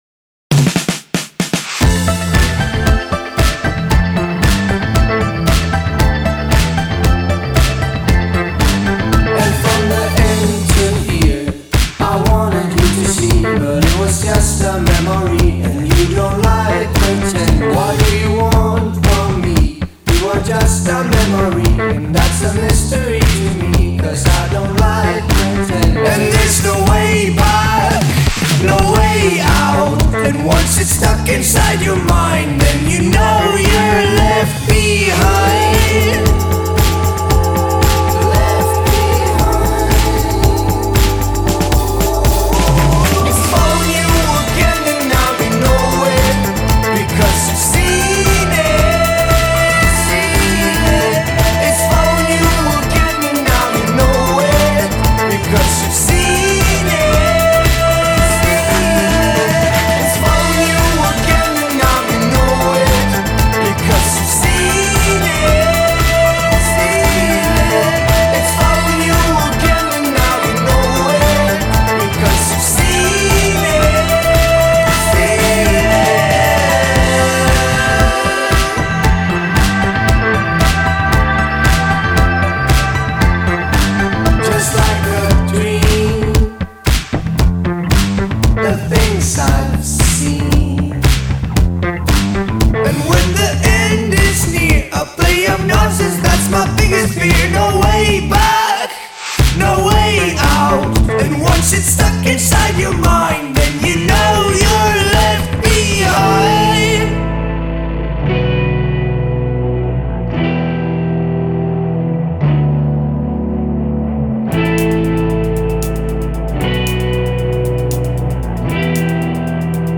educate and fortify us with lovely electronic noises.